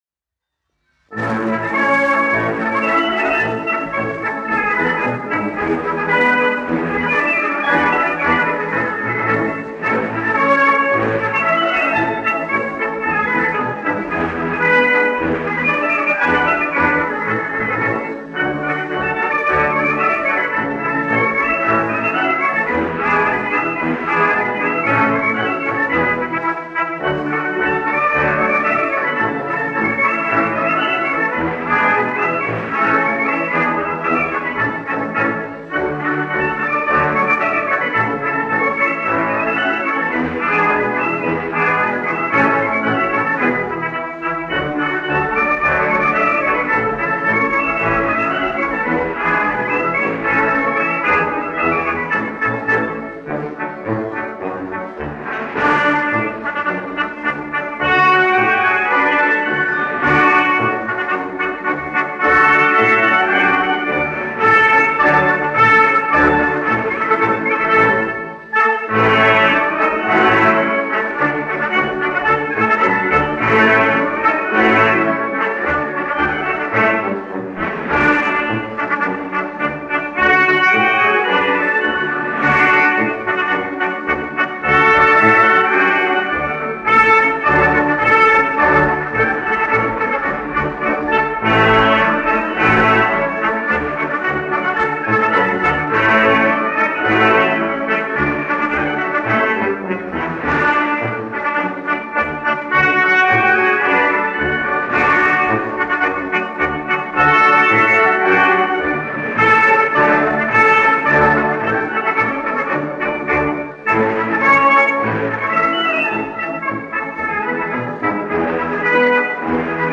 1 skpl. : analogs, 78 apgr/min, mono ; 25 cm
Marši
Pūtēju orķestra mūzika
Latvijas vēsturiskie šellaka skaņuplašu ieraksti (Kolekcija)